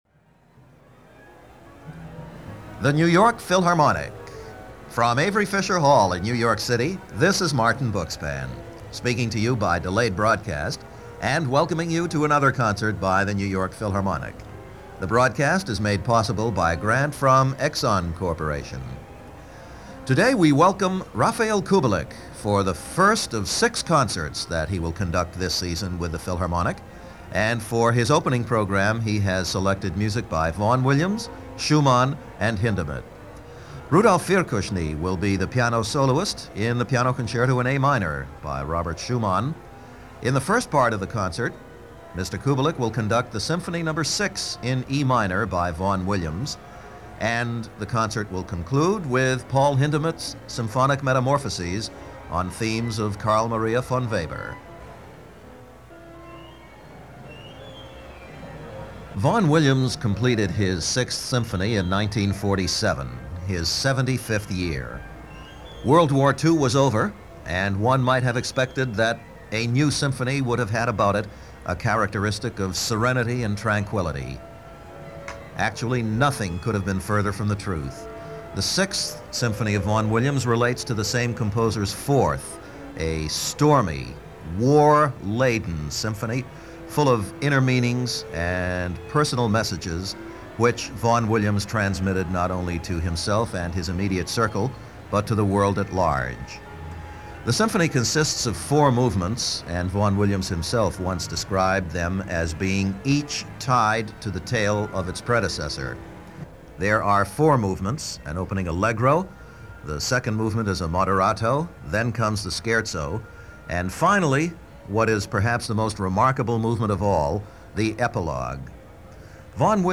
Two giants in music with the New York Philharmonic in a broadcast concert from 1978.
He is joined by Czech Piano legend Rudolf Firkusny in a program of music by Vaughan-Williams, Schumann and Paul Hindemith.